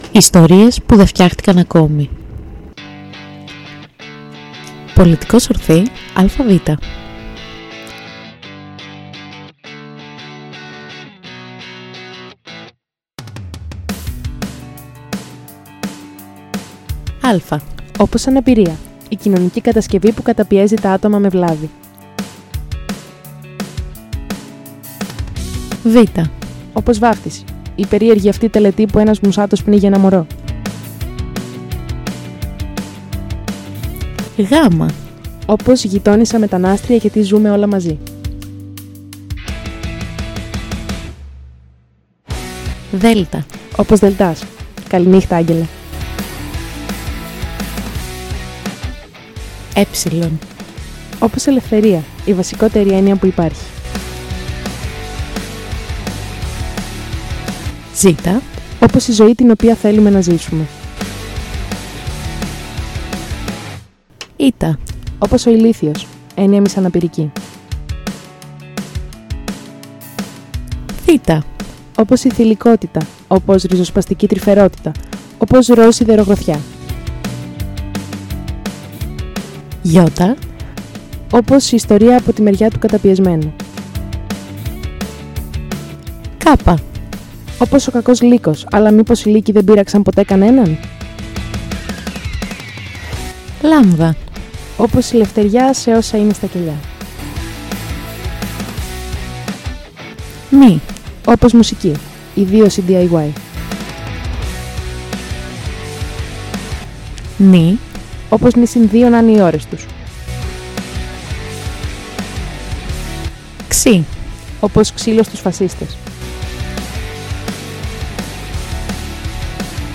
κομμάτι: Avril Lavigne x MGK Pop Punk x Punk Rock x Paramore Guitar Type Beat ‘Bite Me’